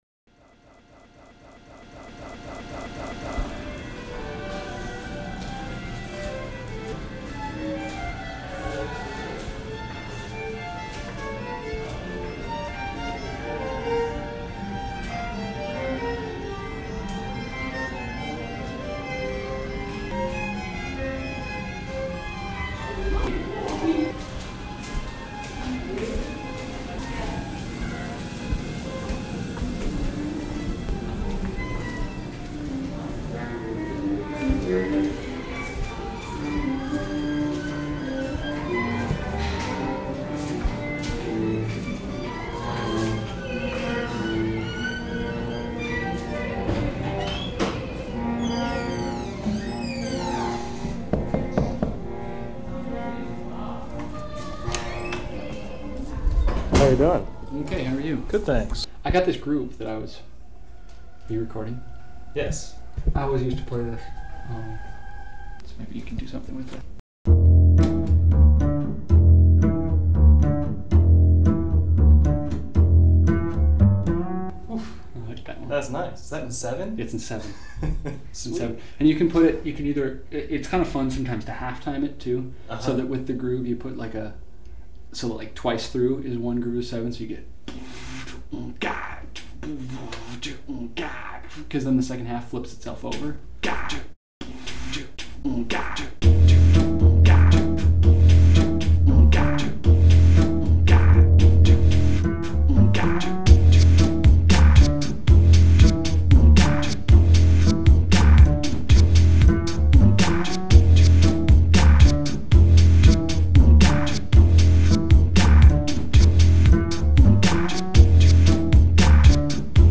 a kick-ass bassist who was studying with richard davis